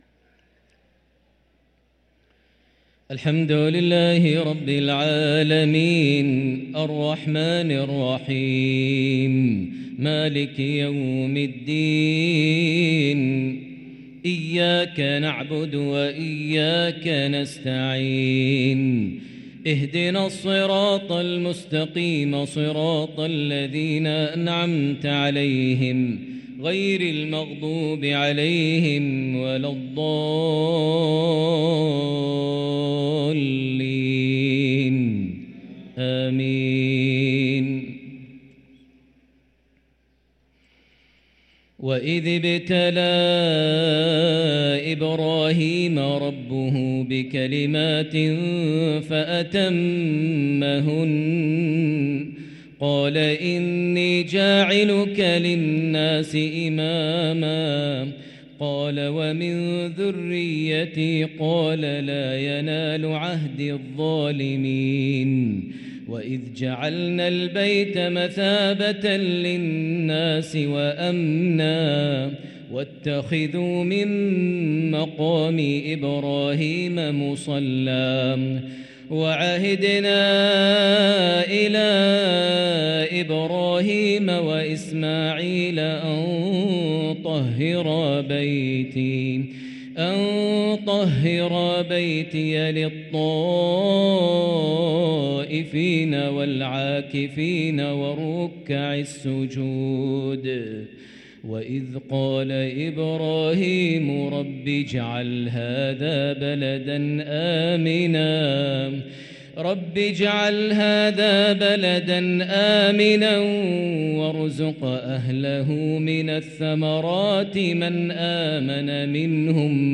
صلاة العشاء للقارئ ماهر المعيقلي 2 شعبان 1444 هـ